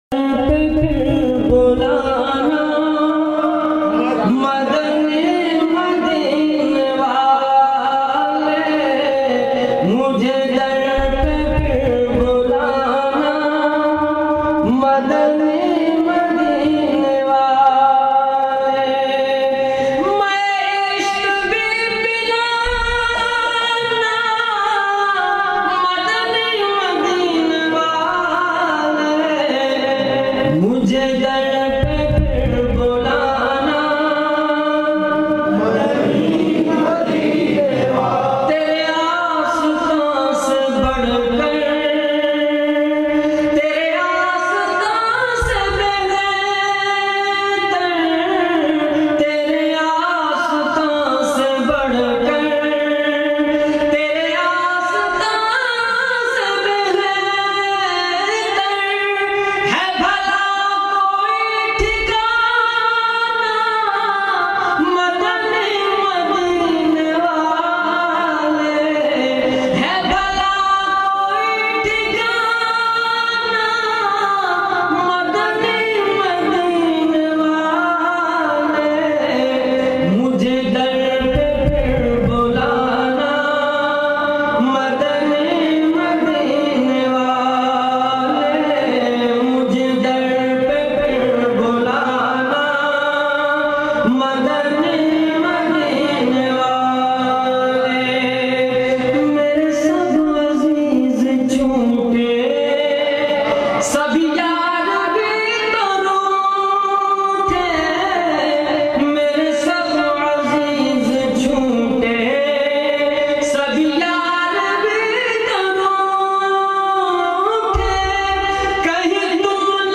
Beautifull voice
in best audiuo quality